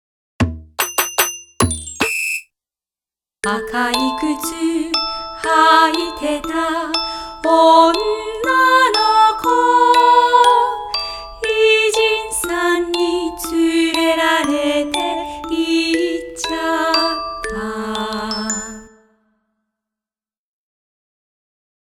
童謡を中心にわらべ歌、唱歌、民謡を収録。